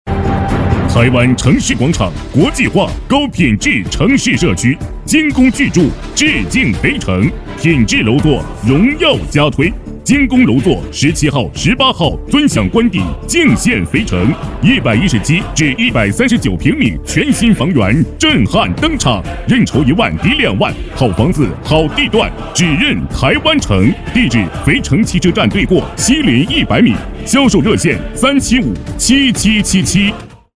B男100号
【广告】楼盘广告demo
【广告】楼盘广告demo.mp3